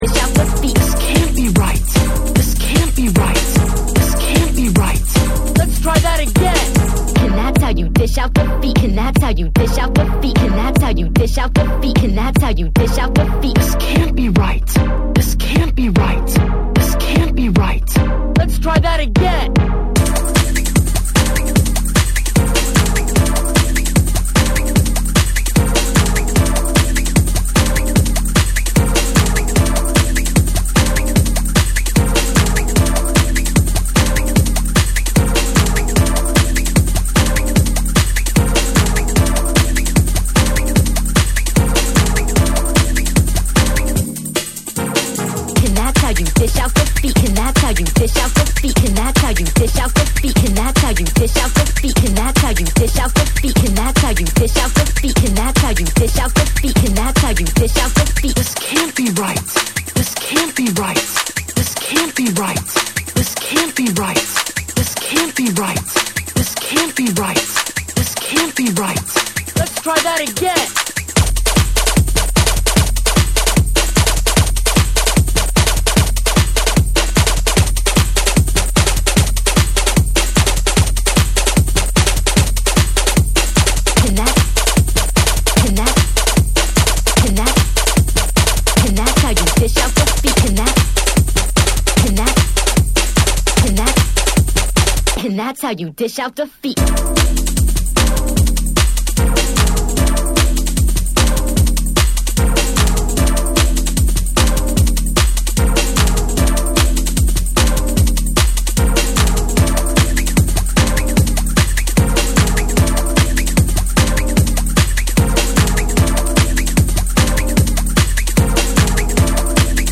TECHNO & HOUSE / BREAKBEATS